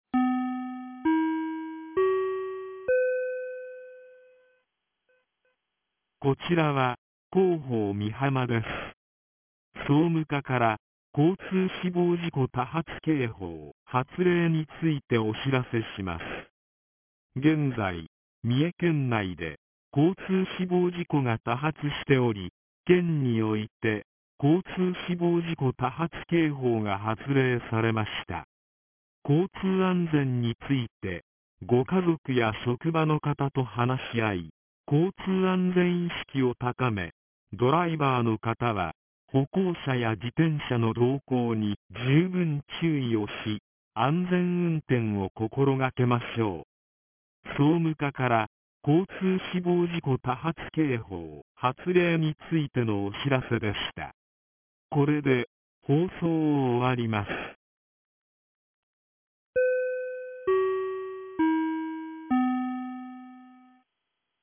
■防災行政無線情報■ | 三重県御浜町メール配信サービス